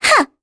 Talisha-Vox_Attack5_kr.wav